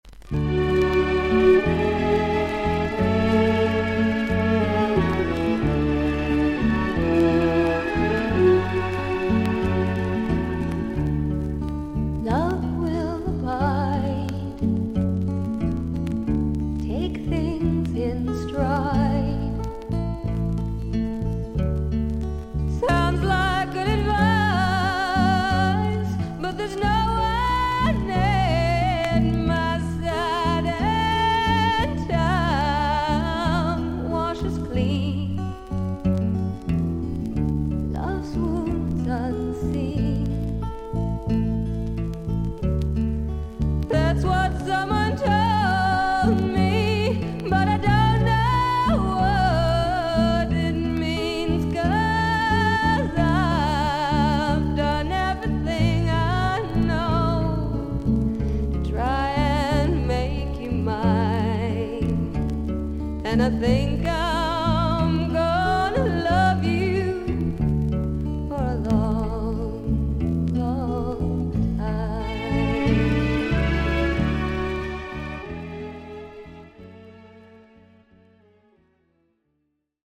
アメリカ盤 / 12インチ LP レコード / ステレオ盤
少々軽いパチノイズの箇所あり。少々サーフィス・ノイズあり。クリアな音です。